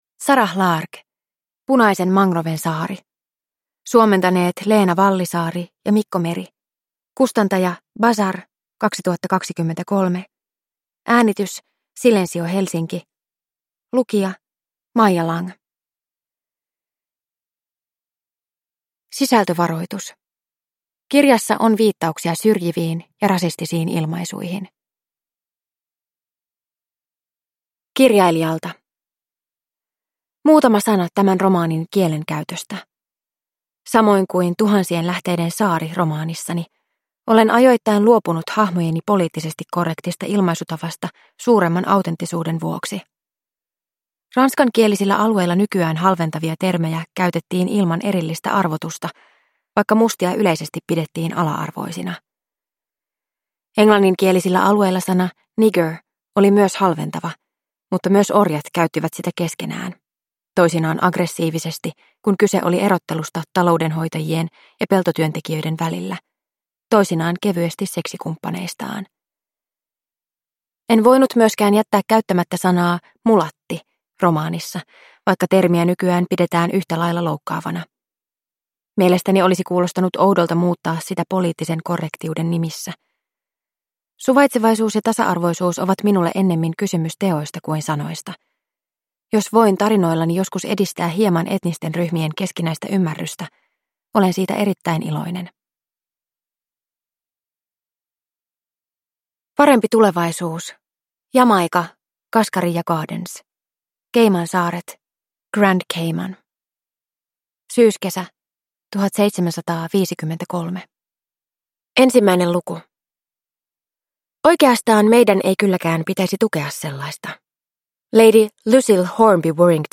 Punaisen mangroven saari – Ljudbok – Laddas ner